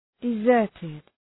{dı’zɜ:rtıd}